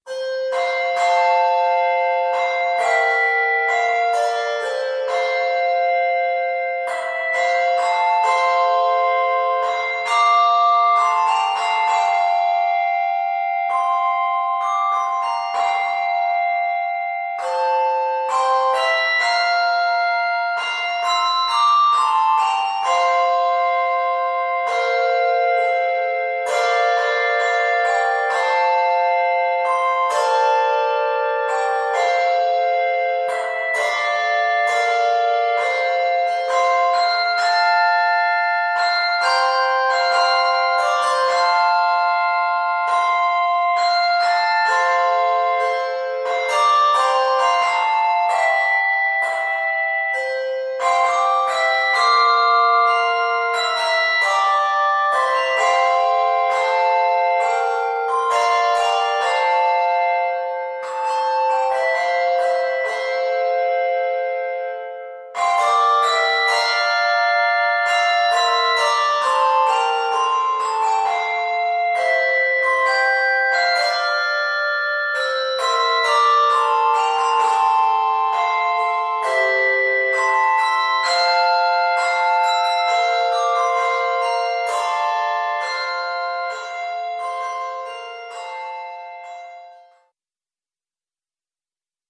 4-in-hand ensemble